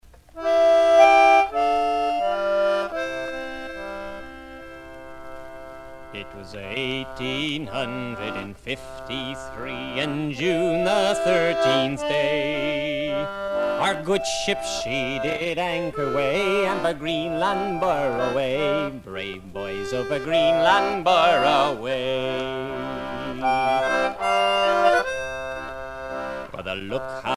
Sea chanteys and sailor songs
Pièce musicale éditée